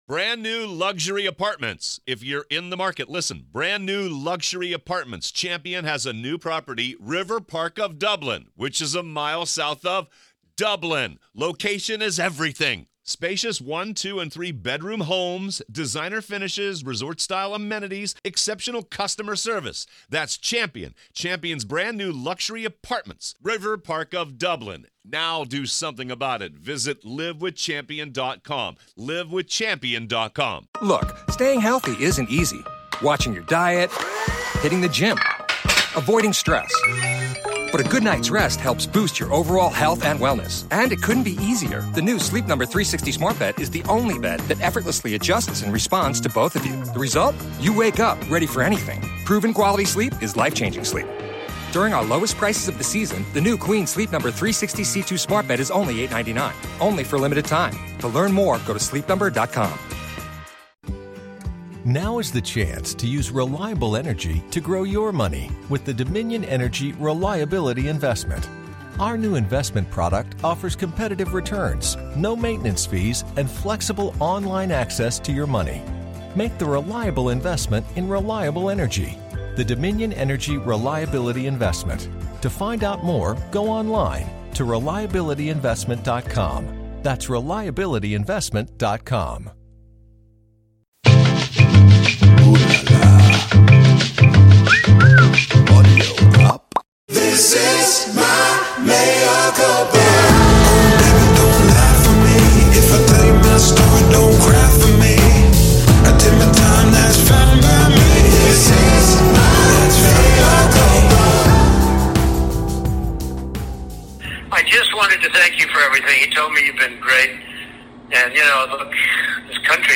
Michael speaks with former Obama White House Ethics Czar and Special Counsel to the House Judiciary Committee Norman Eisen.